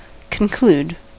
Ääntäminen
IPA : /kən.ˈkluːd/